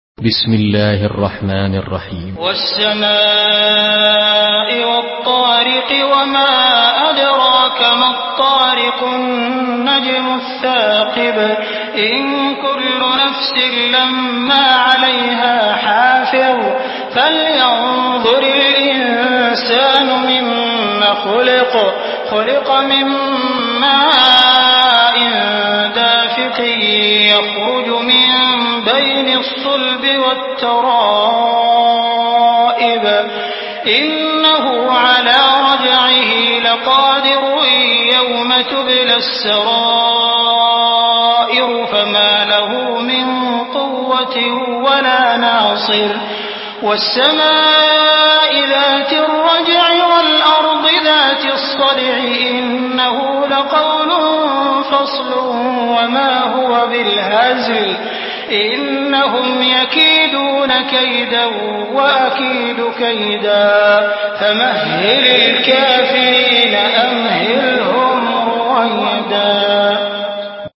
Surah আত-ত্বারেক MP3 by Abdul Rahman Al Sudais in Hafs An Asim narration.
Murattal Hafs An Asim